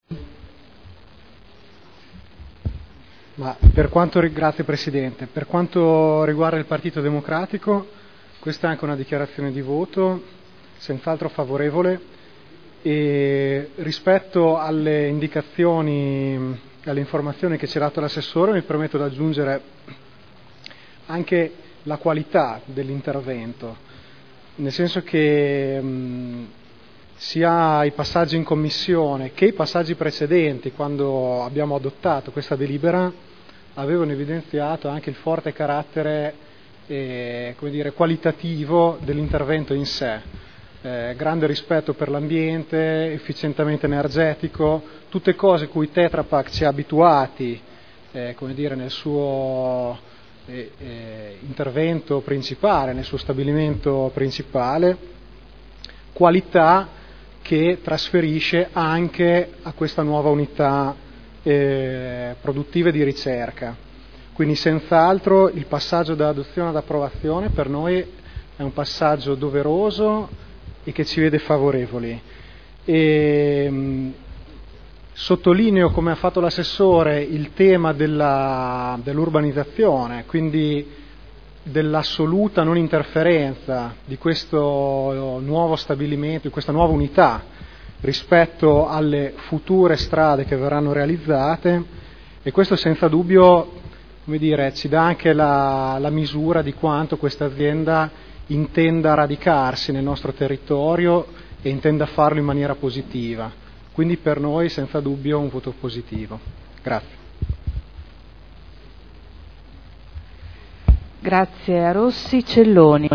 Seduta del 30/05/2011. Dibattito su proposta di deliberazione: Variante al POC-RUE – Area in via Emilia Ovest – Z.E. 1481-1502 – Approvazione